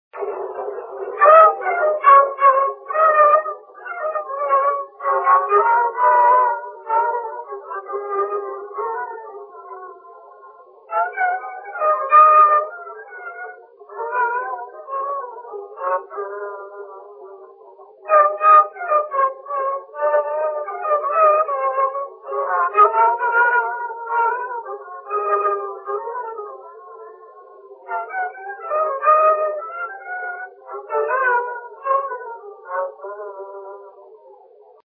Dallampélda: Hangszeres felvétel
Dallampélda: Hangszeres felvétel Erdély - Csík vm. - Csíkszenttamás Műfaj: Hallgató nóta Gyűjtő: Dincsér Oszkár Gyűjtési idő: ismeretlen Médiajelzet: MH-4179a Hangfelvétel: letöltés Szöveg: Hangszeres felvétel